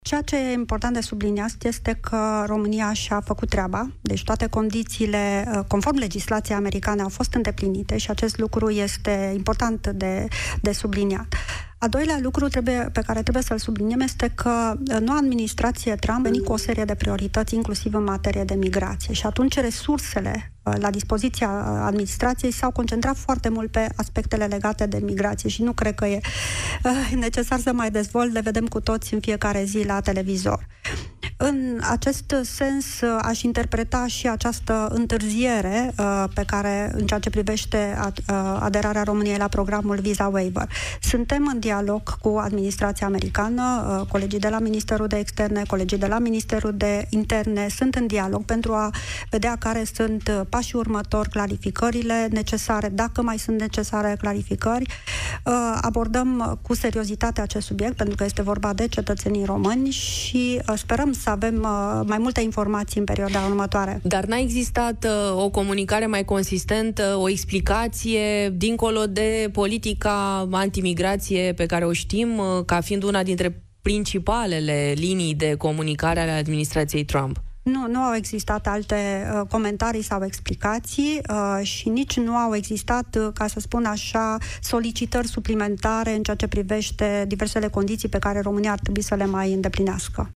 Mădălina Dobrovolschi vorbește cu Luminița Odobescu, consilier prezidențial pentru Afaceri Europene, în cadrul emisiunii „Piața Victoriei” despre ce înseamnă suspendarea aderării României la programul Visa Waiver.